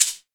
PMARACAS.wav